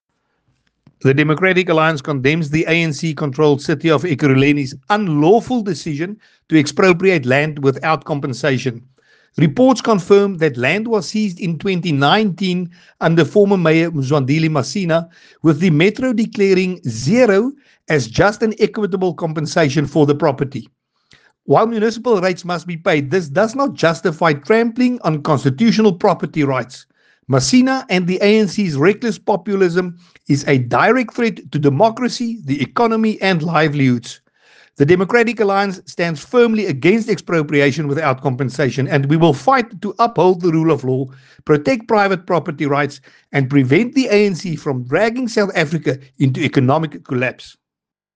Afrikaans soundbites by Willie Aucamp MP.